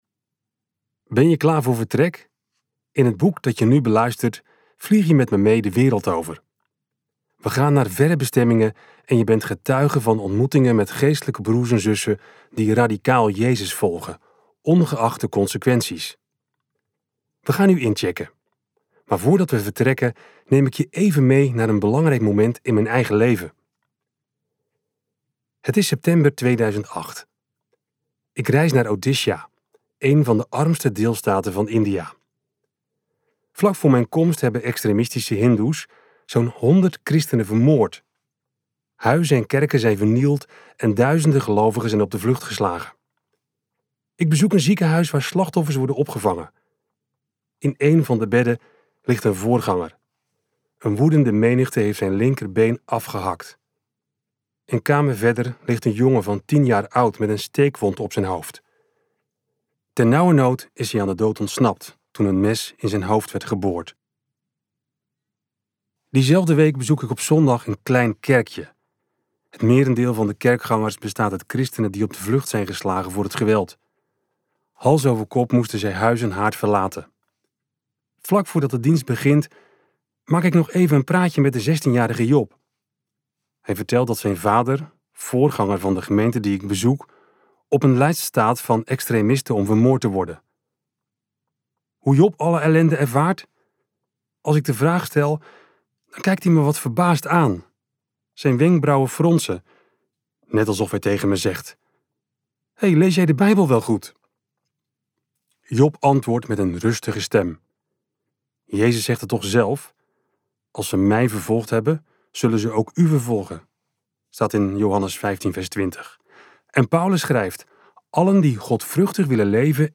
KokBoekencentrum | Diepgeworteld luisterboek